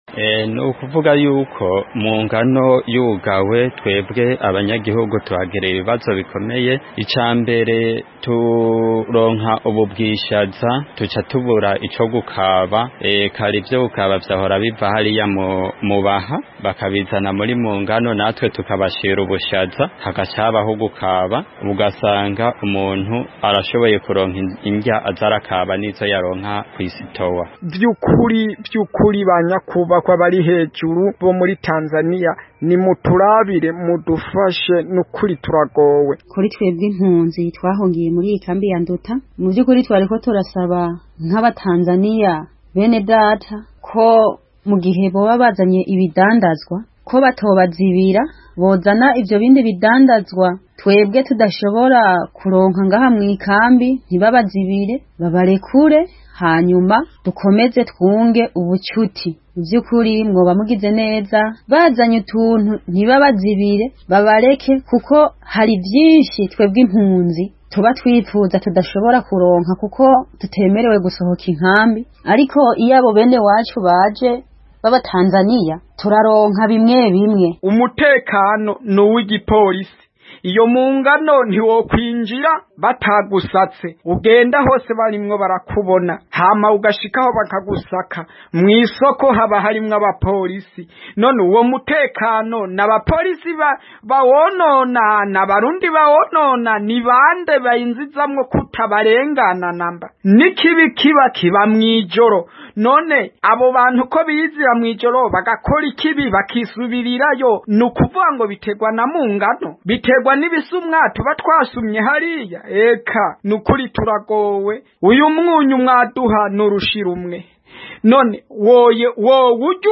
Kurikira ikiganiro cyabo hano munsi.